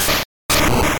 Game: Pokemon SFX Gen 2 - Attack Moves - GSC (GB) (2020)